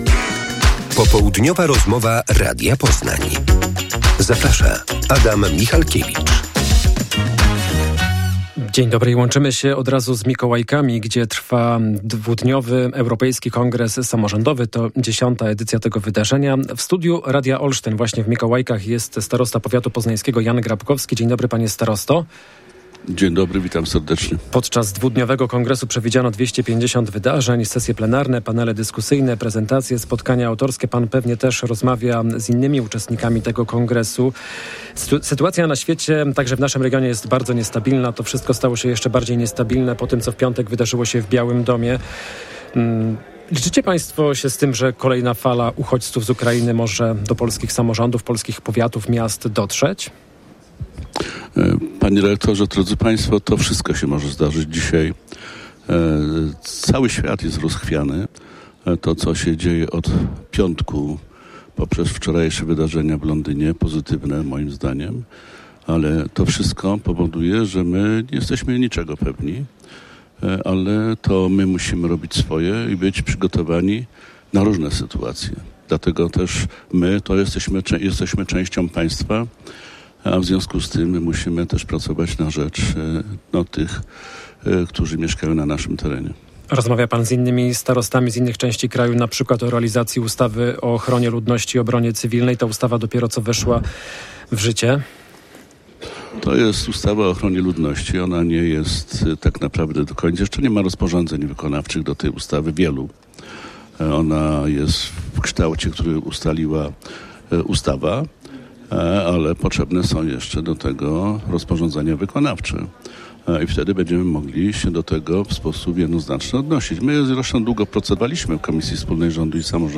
Popołudniowa rozmowa Radia Poznań - Jan Grabkowski